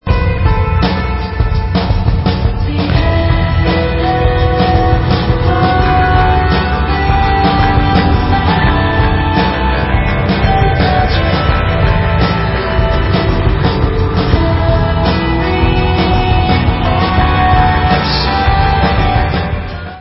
POP WAVE